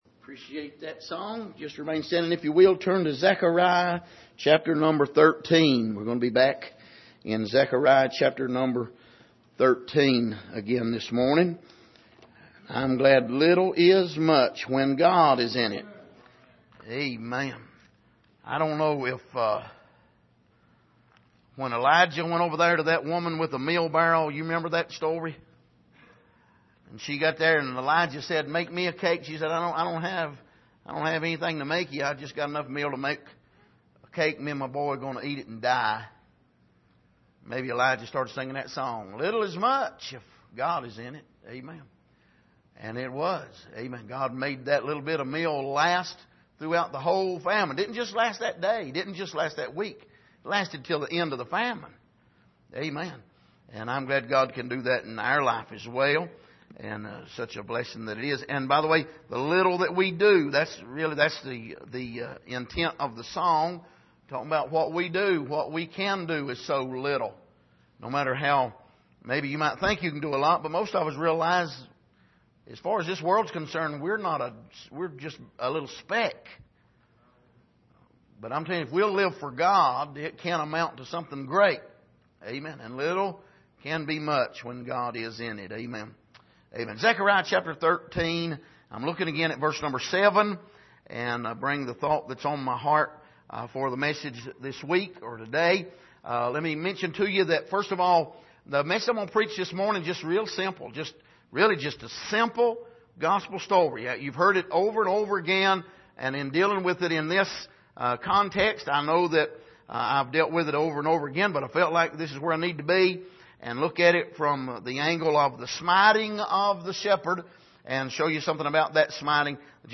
Passage: Zechariah 13:7 Service: Sunday Morning